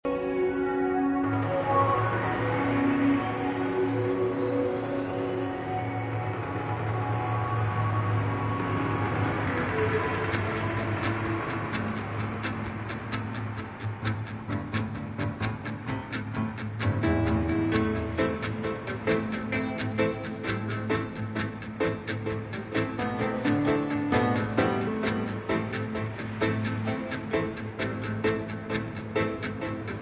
• musica remix
• reggae